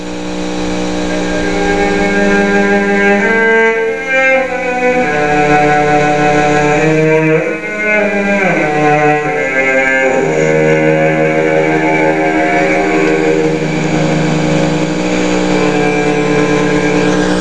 ***This summer at Walden i composed a piece i liked a lot, Piano Trio No. 1. though the following sound bites are not the best quality, I still think you should try and listen to them.
HERE to hear the beginning of the piece...the cello solo.
I am sorry for the quality, but hey, I'm not professional.